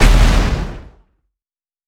sk14_explosion.wav